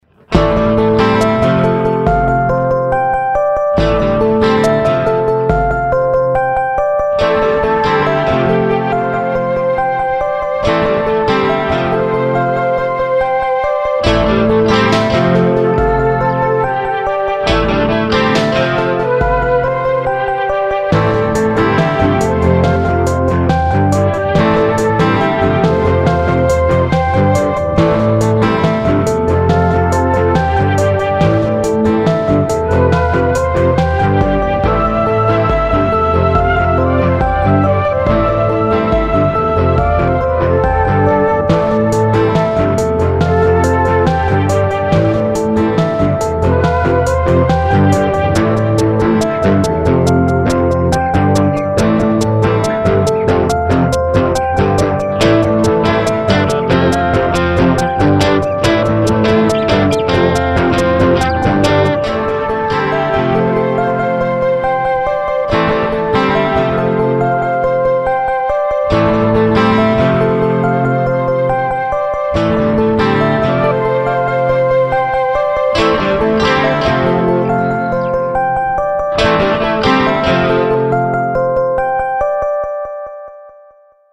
Here is another one of my old video-game-style midis that I rerecorded with my current resources.
there is a continous arpegio bell that is a sequenced synth. The Drums are essentially sequenced.
There are two main guitar tracks that overlap, and three other guitar overdubs for highlights. On all othe guitars i put on the thick Tracktion chorus filter for that eighties, fantasy sound.
The lead is played on my piano with the same Mellotron flute samples I've used before in other tracks.
Filed under: Instrumental Remix | Comments (1)